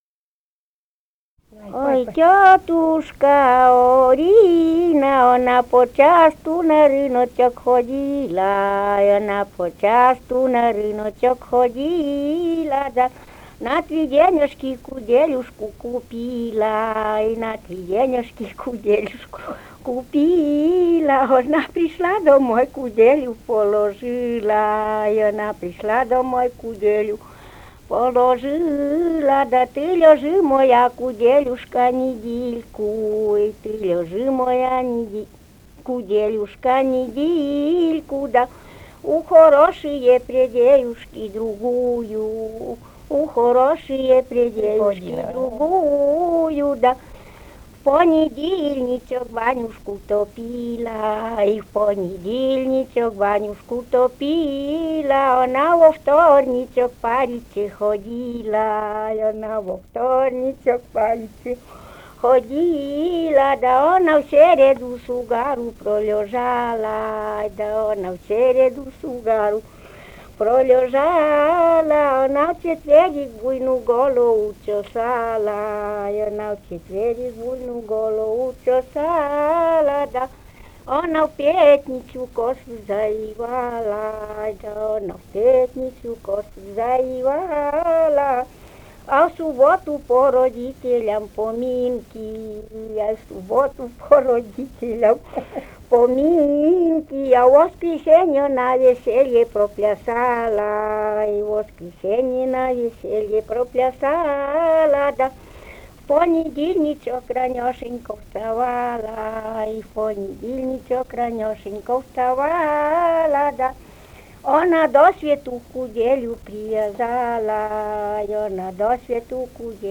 Живые голоса прошлого 150. «Ой, тётушка Орина» (хороводная).